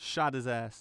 Combat Dialogue